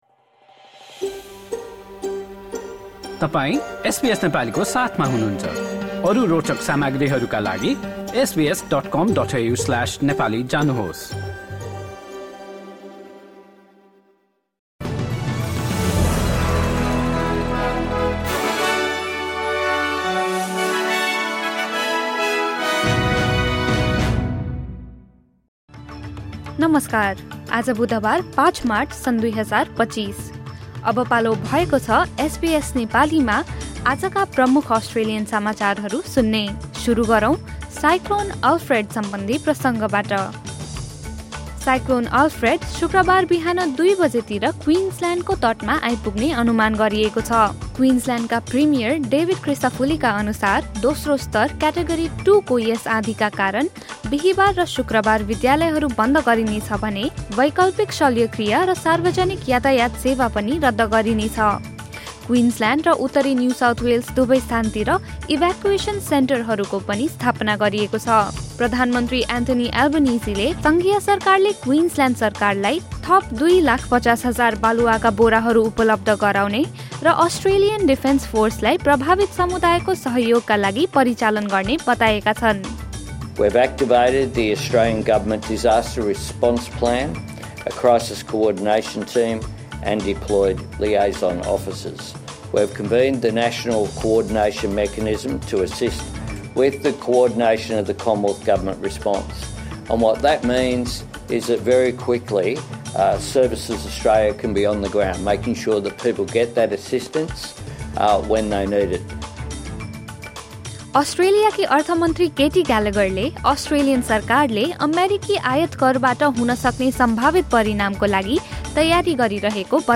SBS Nepali Australian News Headlines: Wednesday, 5 March 2025